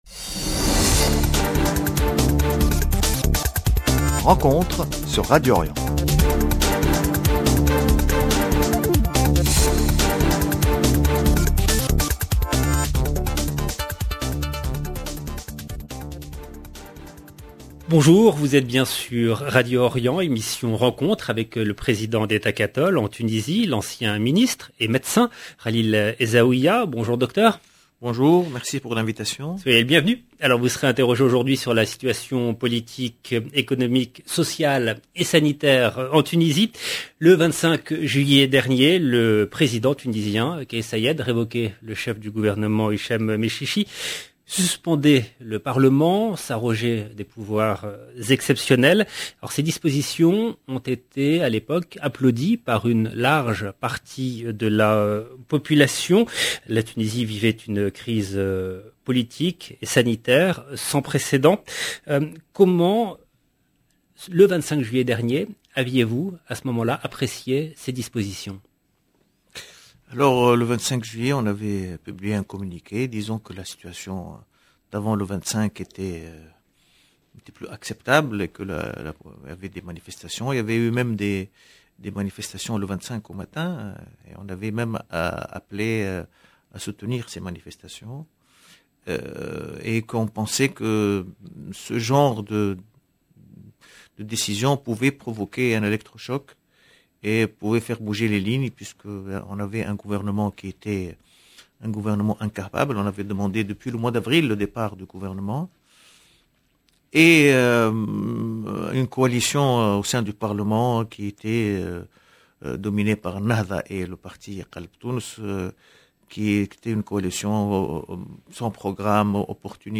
Khalil Zaouia 10 novembre 2021 - 21 min 35 sec Le président du parti Ettakatol en Tunisie, Khalil Zaouia, invité de Rencontre LB RENCONTRE, mercredi 10 novembre 2021 Emission Rencontre avec le président d’Ettakatol en Tunisie, l’ancien ministre et médecin Khalil Zaouia . Une émission sur la situation politique, économique, sociale et sanitaire en Tunisie.